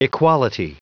Prononciation du mot equality en anglais (fichier audio)
equality.wav